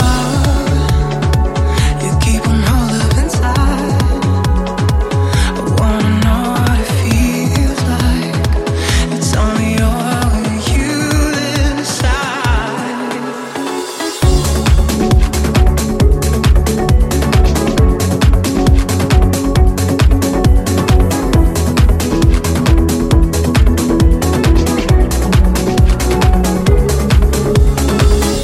Genere: pop,deep,dance,disco,news